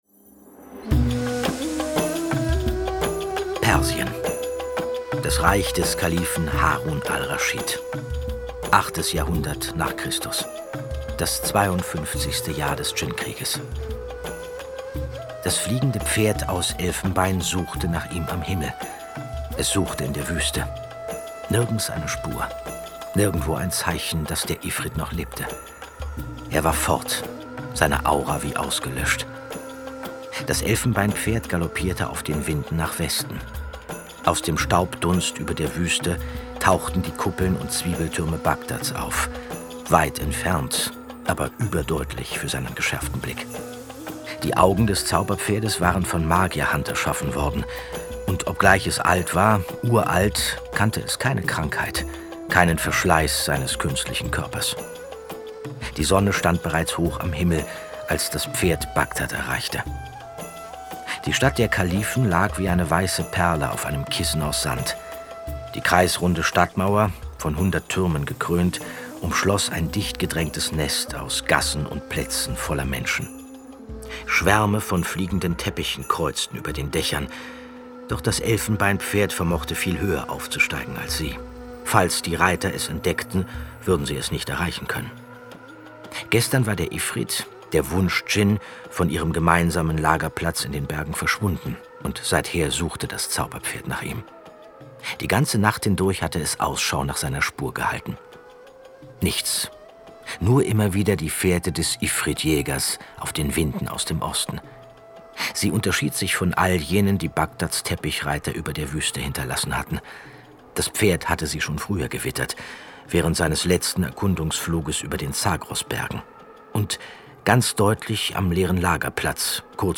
Andreas Fröhlich (Sprecher)